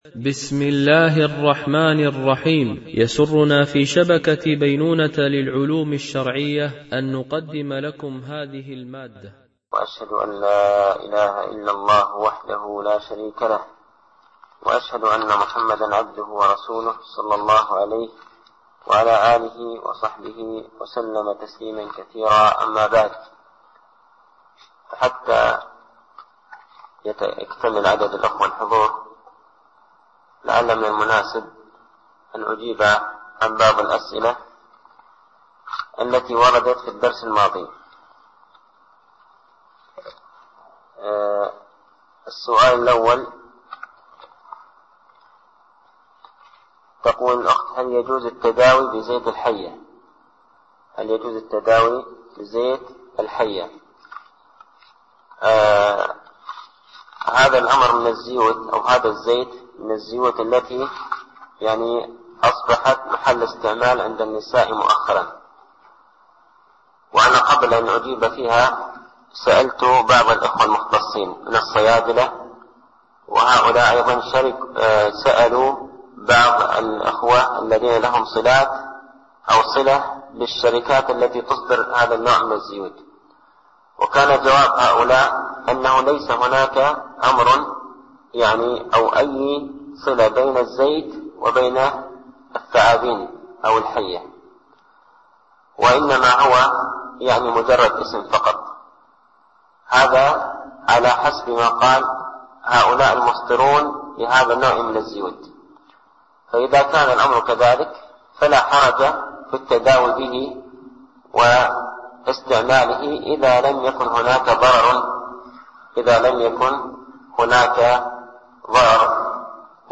) الألبوم: شبكة بينونة للعلوم الشرعية التتبع: 105 المدة: 51:40 دقائق (11.86 م.بايت) التنسيق: MP3 Mono 22kHz 32Kbps (CBR)